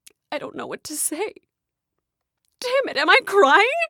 don't-know-what-to-say-crying
Category: Games   Right: Personal